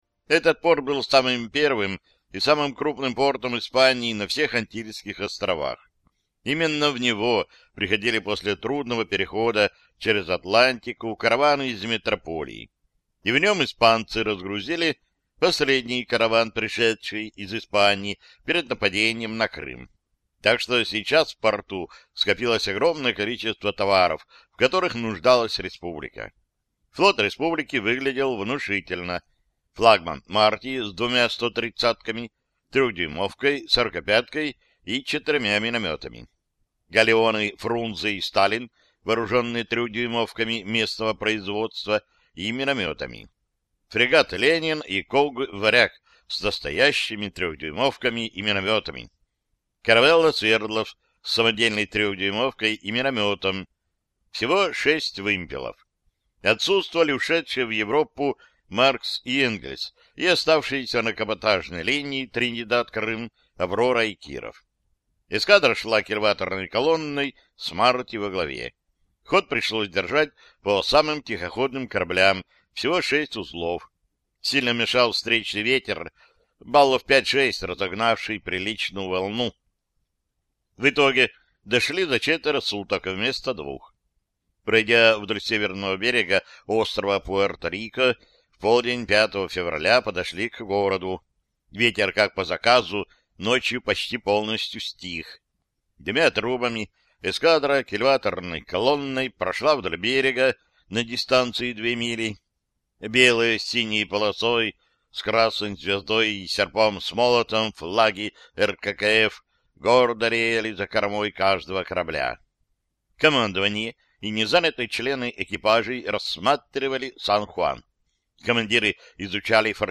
Аудиокнига Коммунистическая республика Камчатка | Библиотека аудиокниг